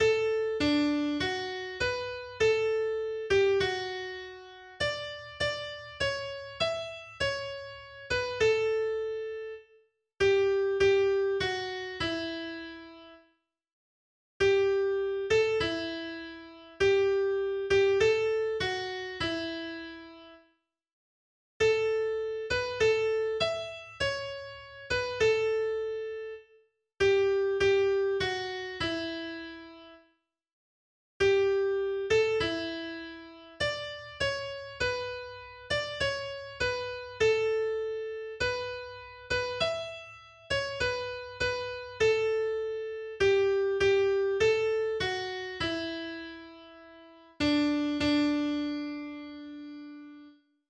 255   "Ivan, go home" (D-Dur, eigene) .pdf .capx .mid